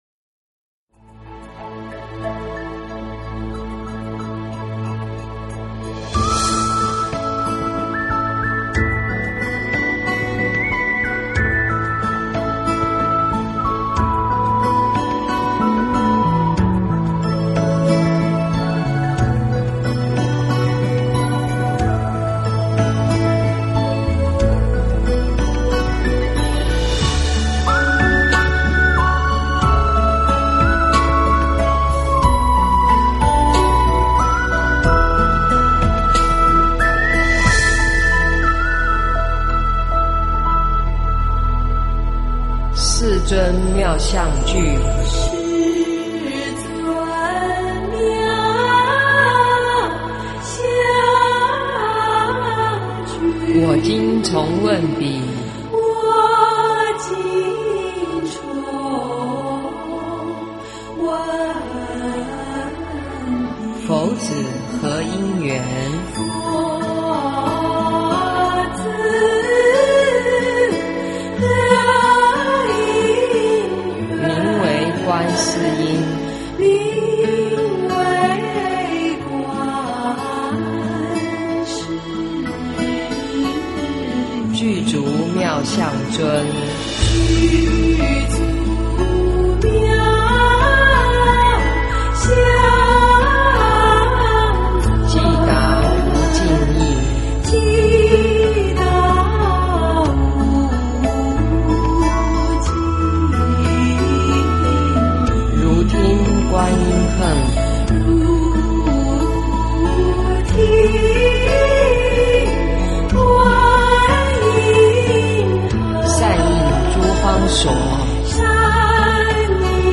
普门颂 - 诵经 - 云佛论坛
普门颂 诵经 普门颂--未知 点我： 标签: 佛音 诵经 佛教音乐 返回列表 上一篇： 佛说除一切疾病陀罗尼经 下一篇： 楞严咒快诵 相关文章 秘厘图--何训田 秘厘图--何训田...